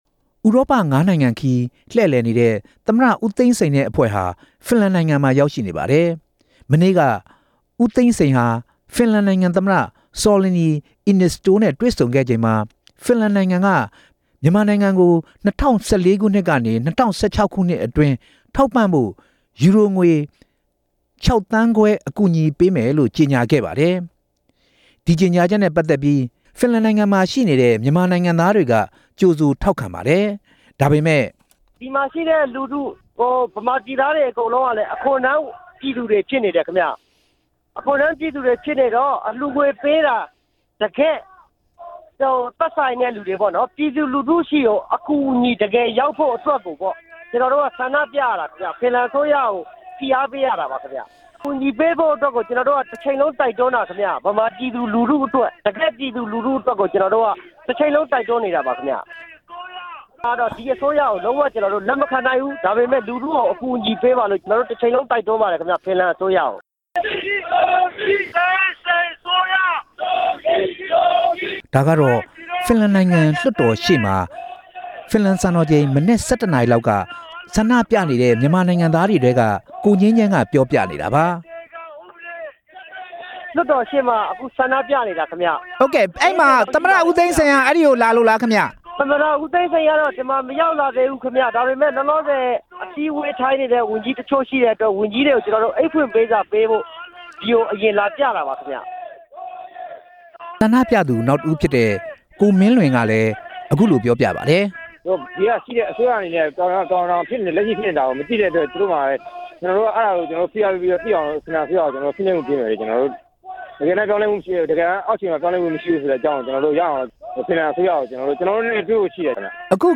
ဖင်လန်ရောက် မြန်မာအစိုးရကို ဆန္ဒပြရခြင်းနဲ့ ပတ်သက်ပြီး မေးမြန်းချက်